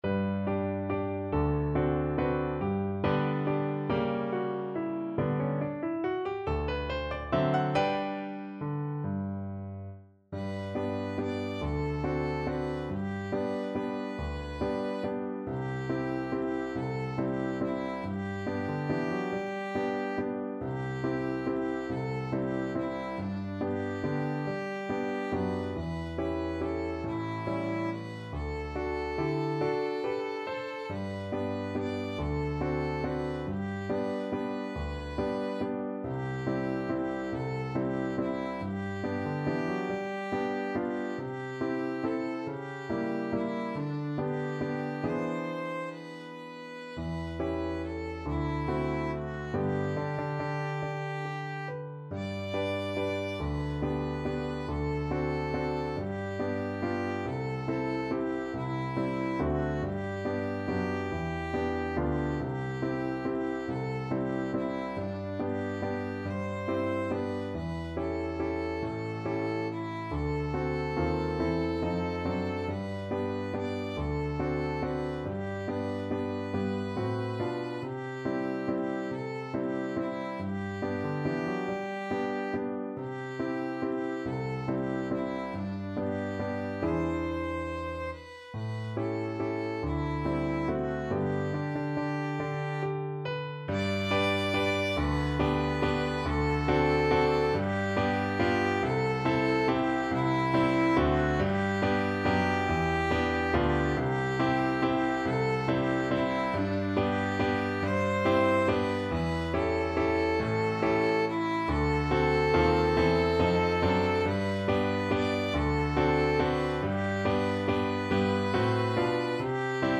3/4 (View more 3/4 Music)
~ = 140 Tempo di Valse